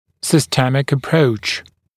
[ˌsɪstə’mætɪk ə’prəuʧ][ˌсистэ’мэтик э’проуч]системный подход, систематический подход